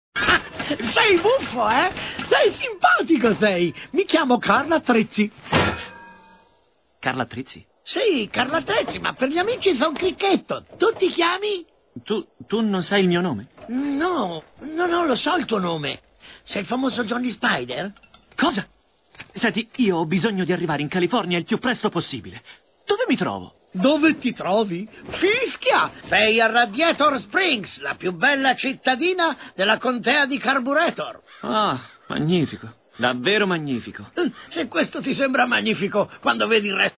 voce di Marco Messeri dal film d'animazione "Cars - Motori ruggenti", in cui doppia Carl Attrezzi.